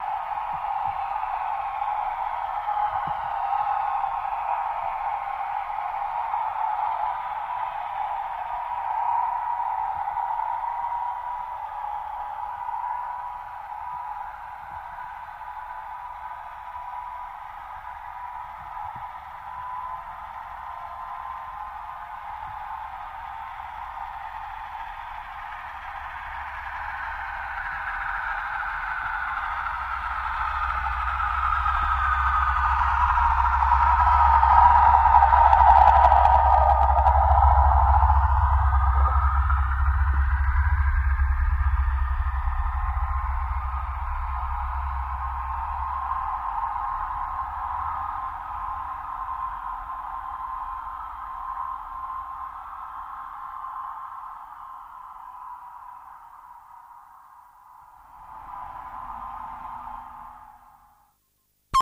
Torpedo Jet By